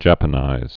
(jăpə-nīz)